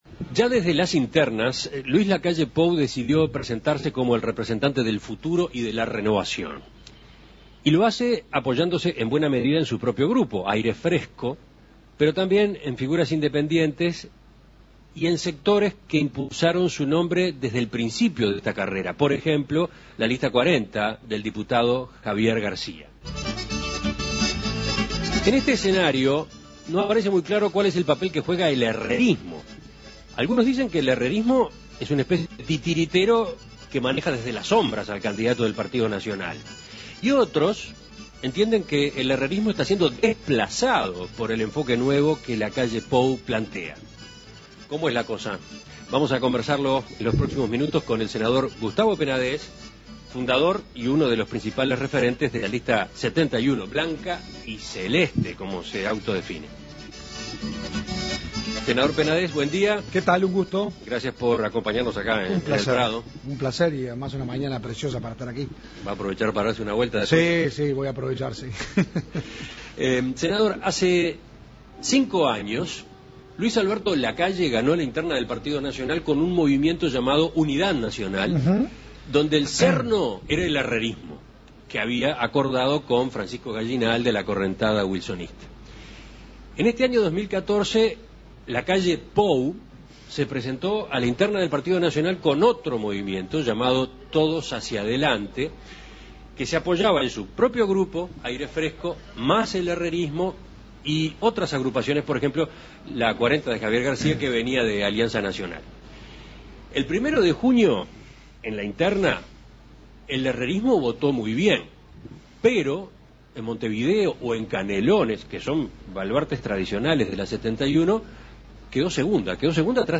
En Perspectiva dialogó con el senador blanco Gustavo Penadés sobre el rol del Herrerismo en la campaña.